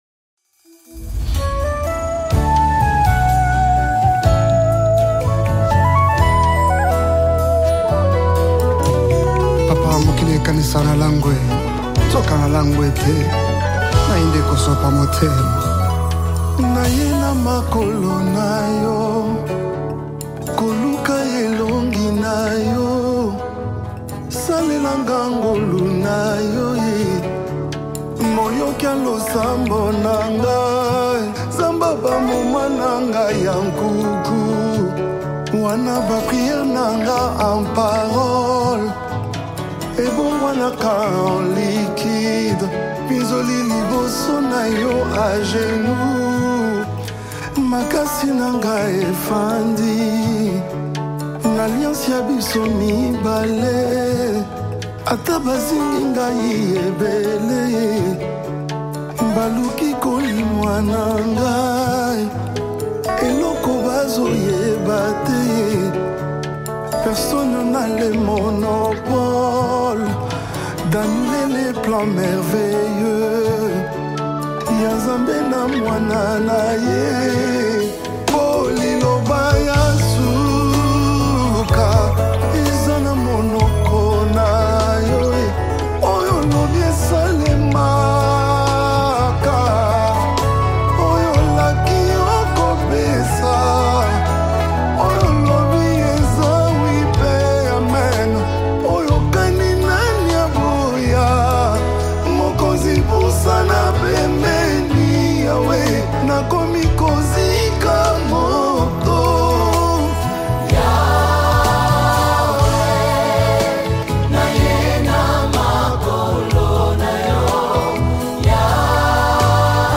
Congo Gospel Music
worship song
With EMOTIVE VOCALS
and a MEDITATIVE WORSHIP ATMOSPHERE